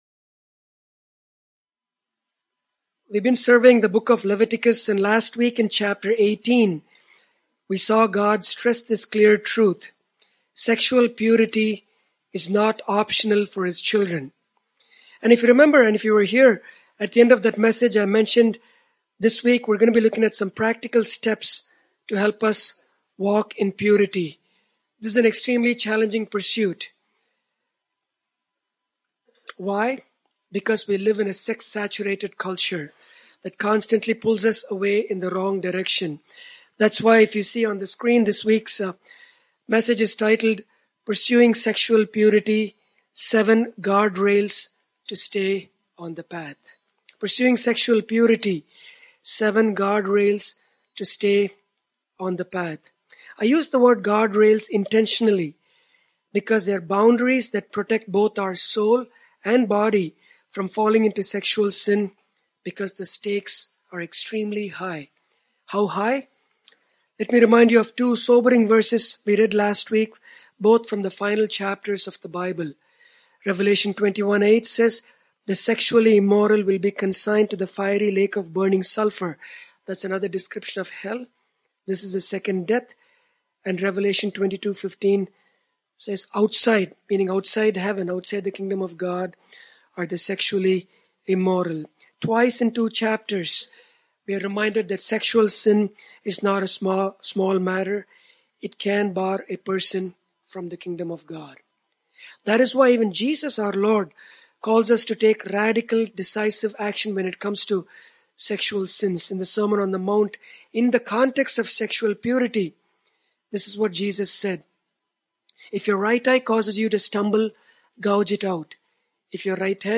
In a world that constantly pulls us toward compromise, how do we stay on the narrow path of sexual purity? In this sermon, we explore 7 practical and biblical guardrails designed to protect your heart, soul, and body from falling into sexual sin.